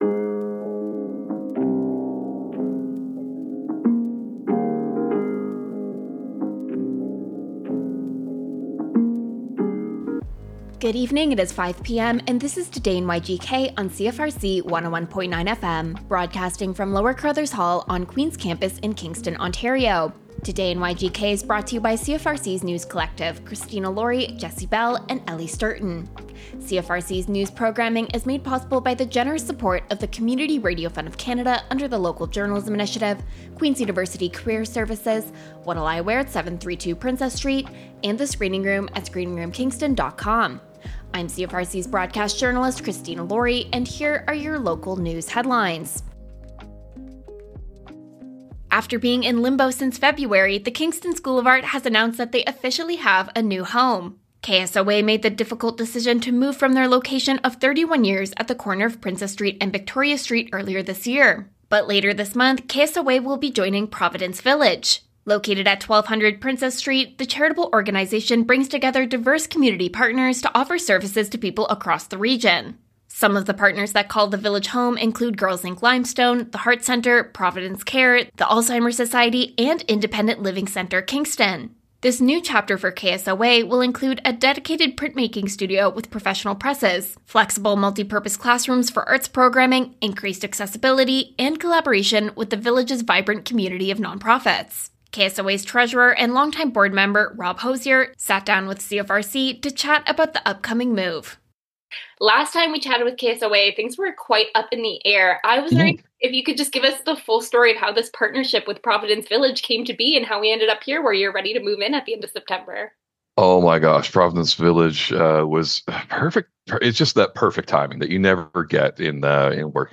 Local news